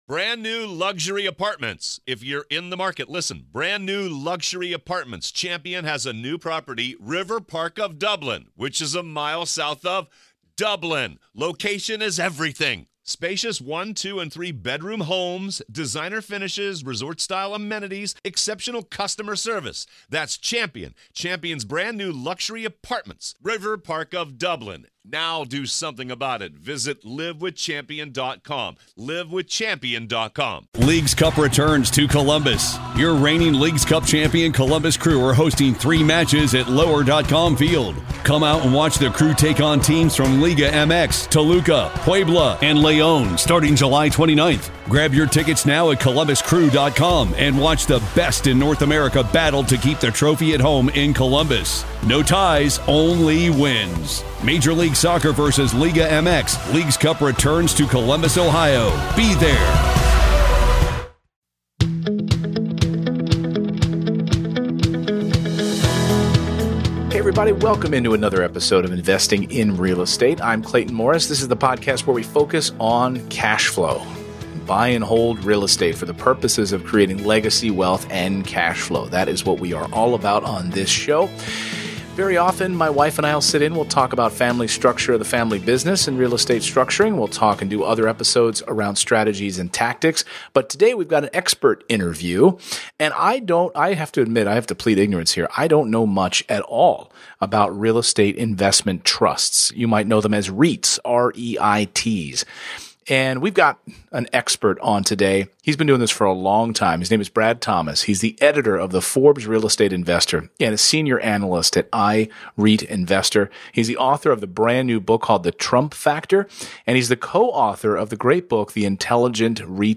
EP107: The Intelligent REIT Investor - Interview